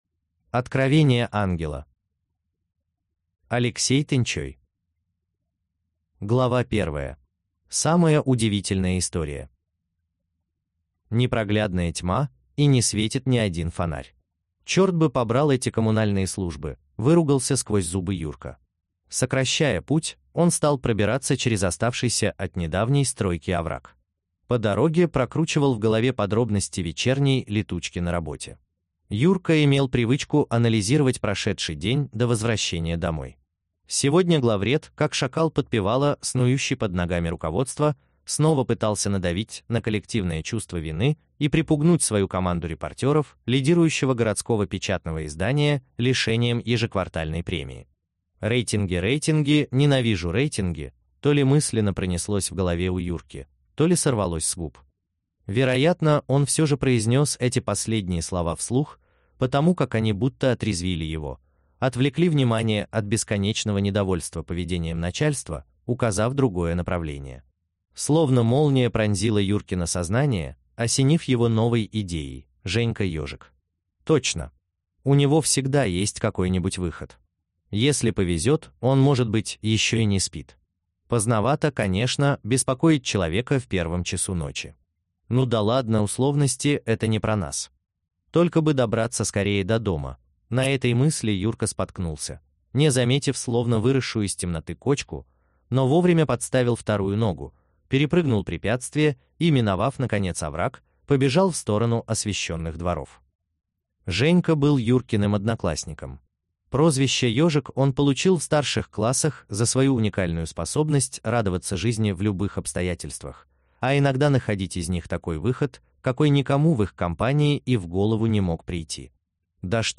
Аудиокнига Откровение ангела | Библиотека аудиокниг